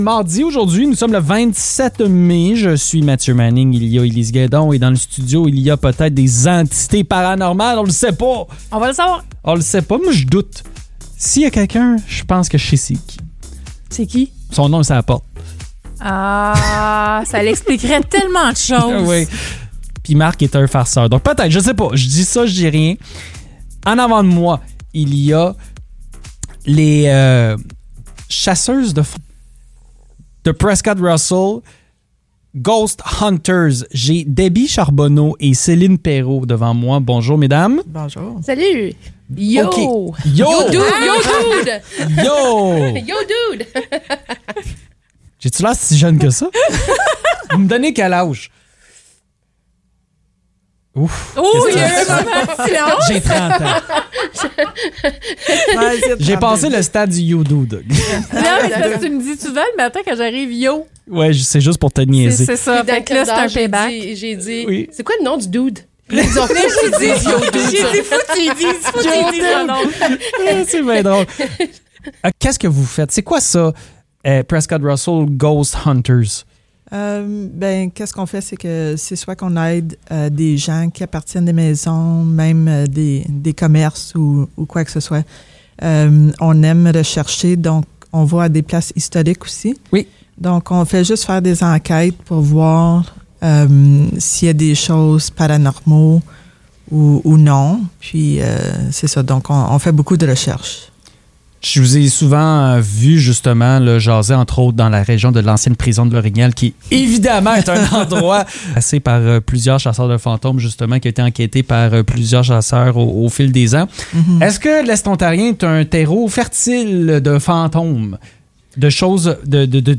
L'équipe de Prescott-Russell Ghost Chasers était en studio pour nous parler de leur travail et déterminer si le studio de GO FM est hanté!